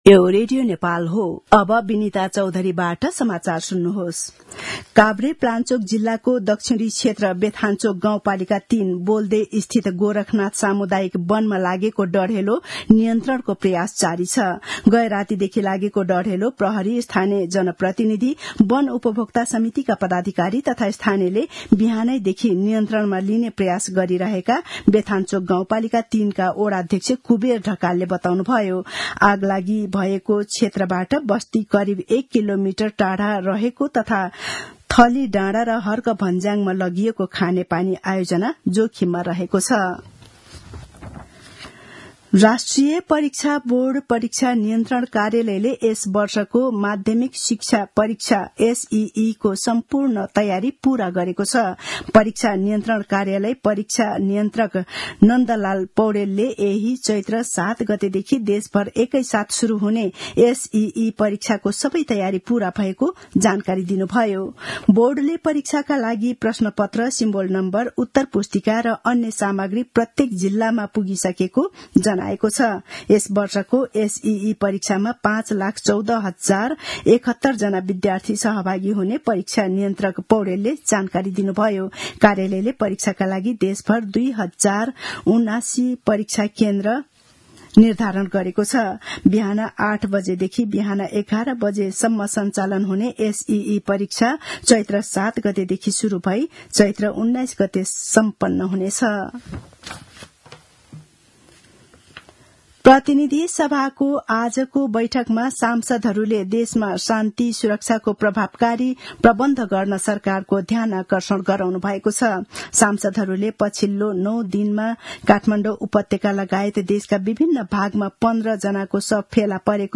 दिउँसो १ बजेको नेपाली समाचार : ४ चैत , २०८१
1-pm-news-3.mp3